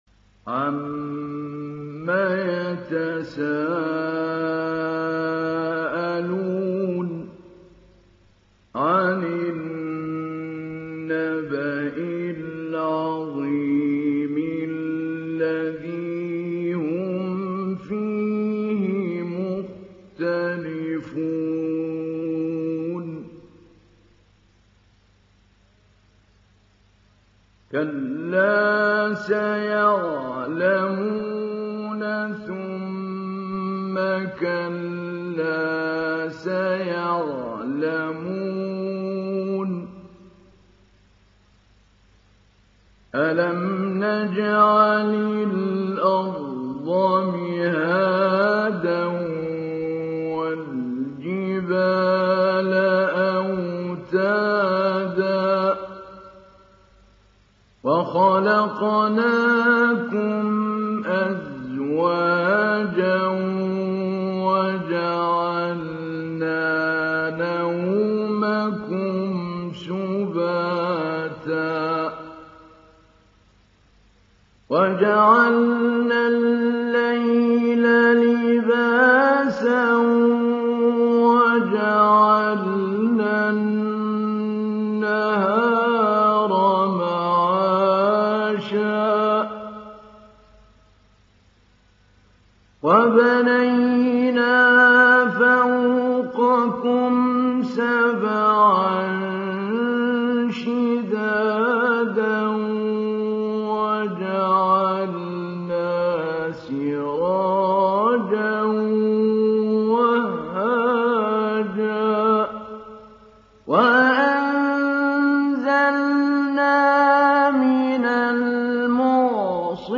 ডাউনলোড সূরা আন-নাবা Mahmoud Ali Albanna Mujawwad